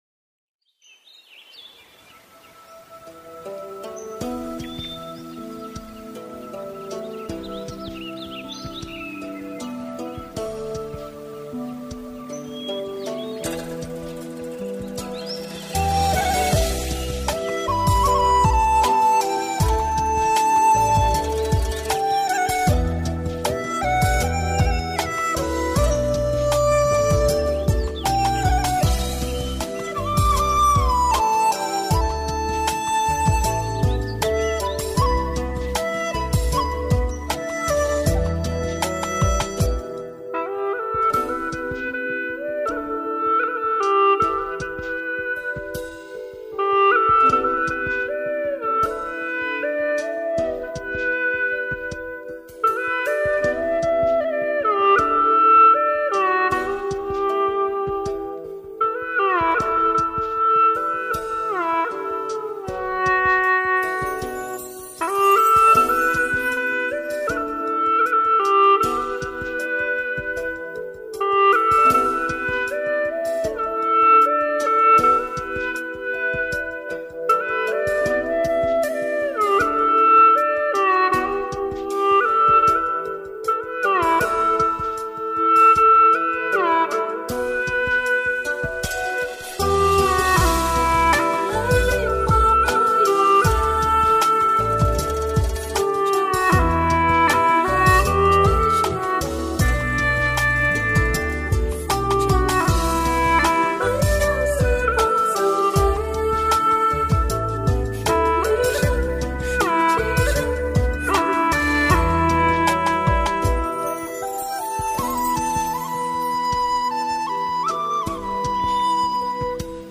调式 : G 曲类 : 古风